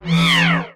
head_drop.ogg